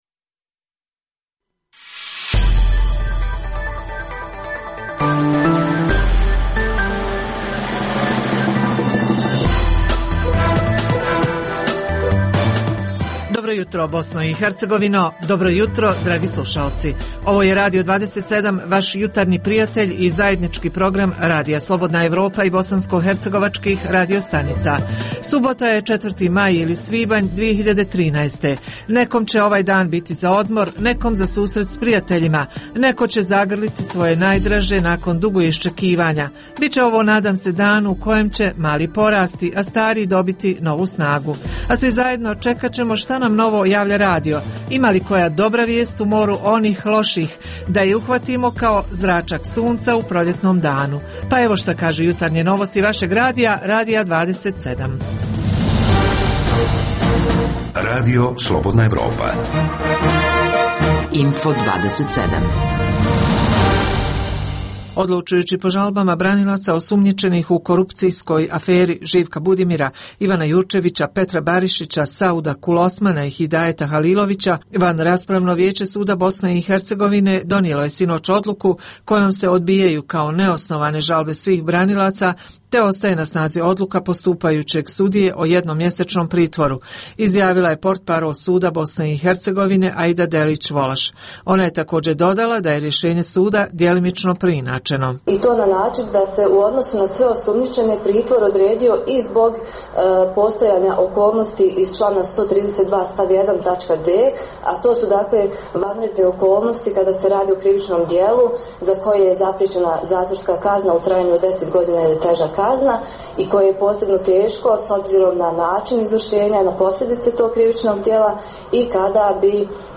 Uz tri emisije vijesti, tu je i izbor muzike za prijatno, jutarnje raspoloženje.